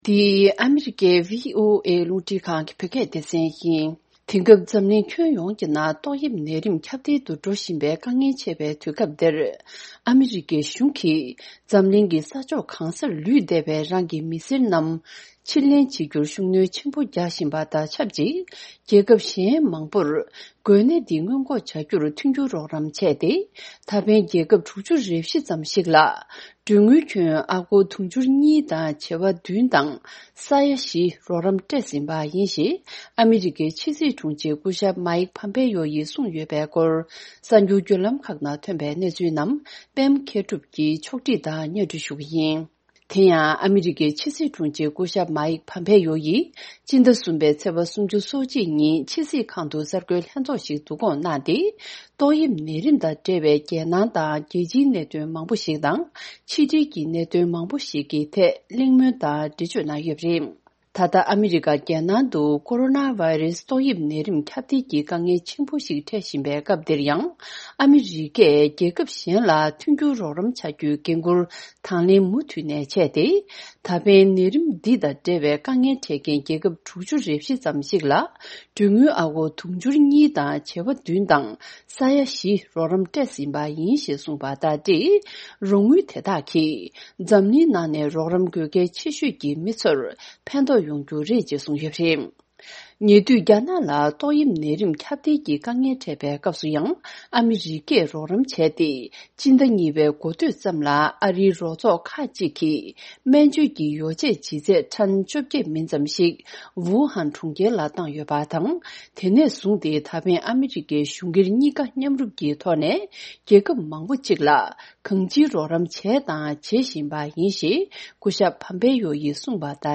ཕྱོགས་བསྒྲིགས་དང་སྙན་སྒྲོན་ཞུ་ཡི་རེད།།